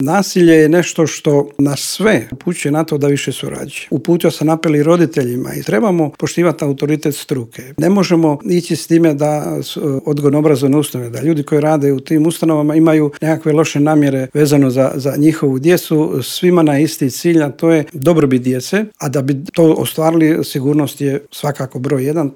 Teme su to o kojima smo u Intervjuu Media servisa razgovarali s ravnateljem Uprave za potporu i unaprjeđenje sustava odgoja i obrazovanja u Ministarstvu obrazovanja Momirom Karinom.